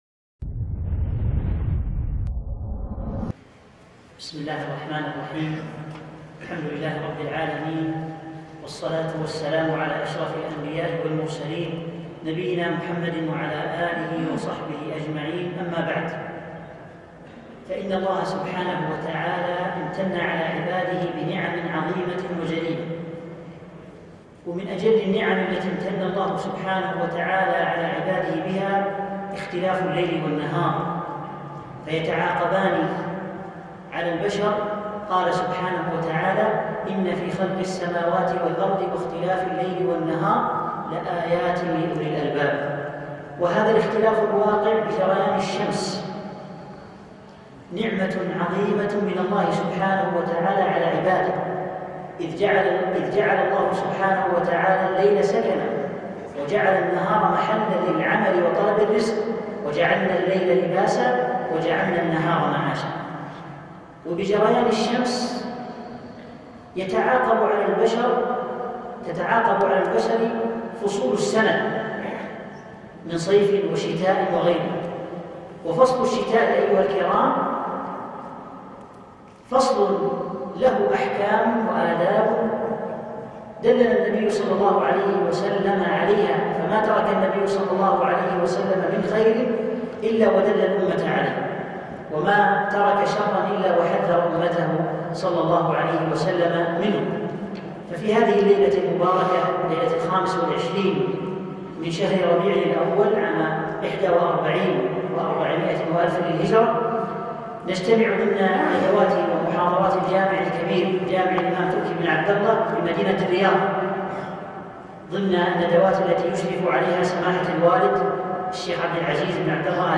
محاضرة - أحكـام الشتاء